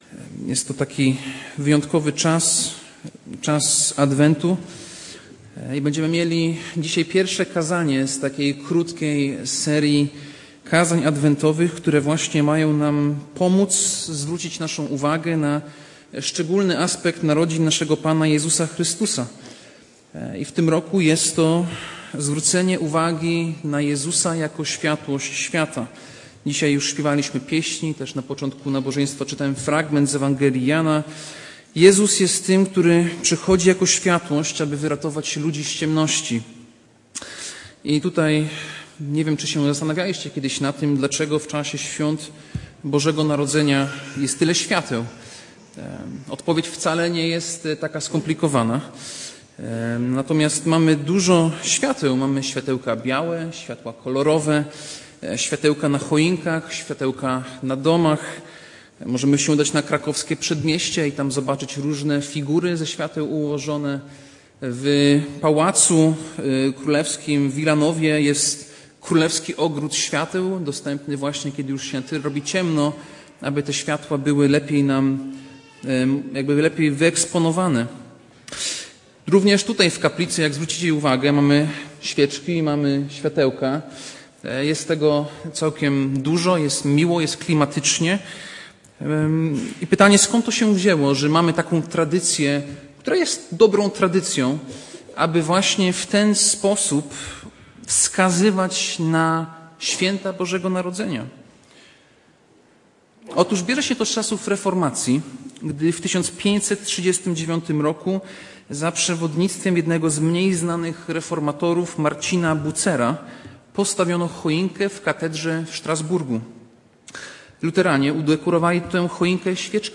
Kazanie